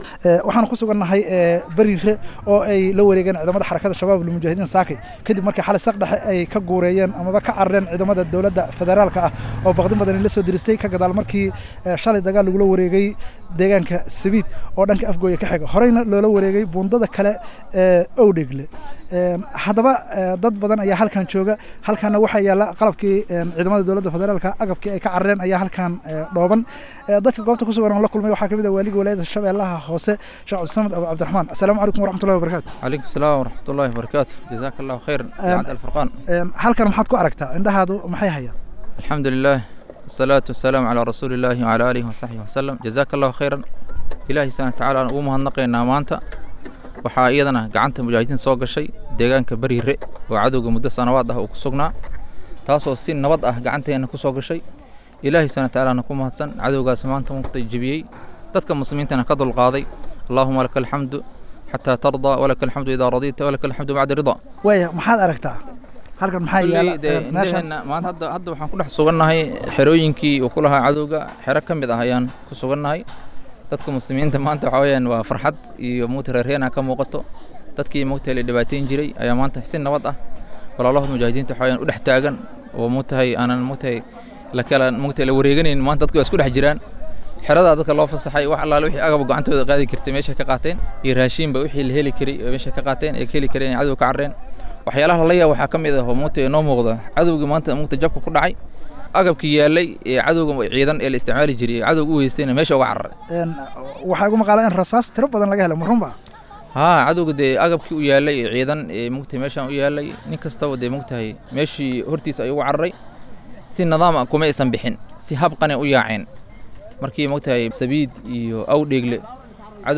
Idaacadda Islaamiga ah ee Al-Furqaan ayaa wareysi kooban la yeelatay Waaliga gobol Sh.Hoose oo ku sugan gudaha xeradii ay ciidanka Dowladda Fedaraalku ka deganaayeen degaanka Bariirre ee gobolka Sh.Hoose.